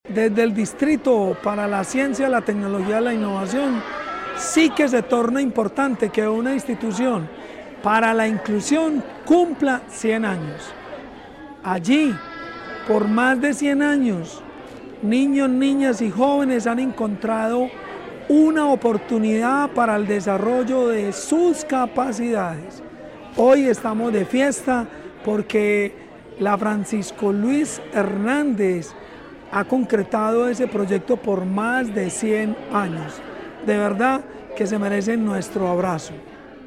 Palabras de Jorge Iván Ríos Rivera, subsecretario de la Prestación del Servicio Educativo